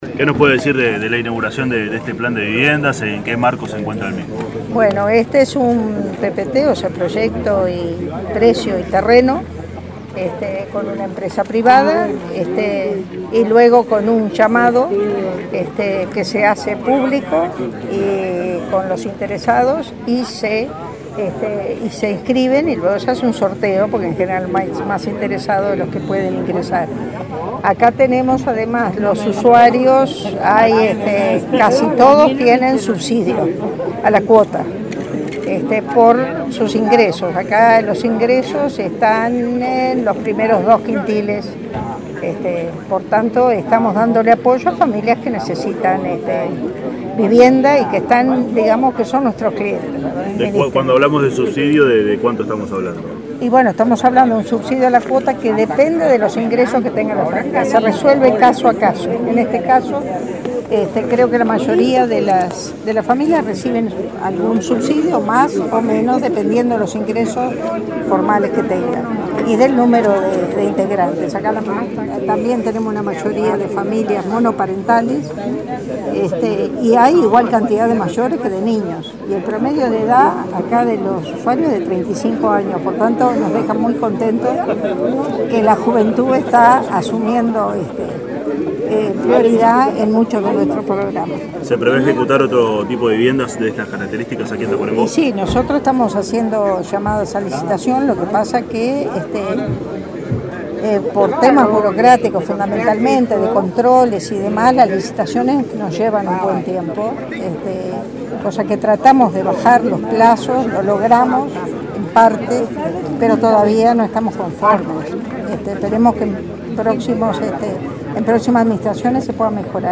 “Las 17 familias que vivirán en el complejo habitacional Tomás de Mattos, en Tacuarembó, tendrán subsidio a la cuota de acuerdo con la composición del núcleo familiar y de sus salarios”, destacó la ministra de Vivienda, Eneida de León, al inaugurar el edifico este jueves 17. En diálogo con la prensa, también adelantó que en el período se construirá un 40 % más de cooperativas de las previstas, y se alcanzarán las 14.000.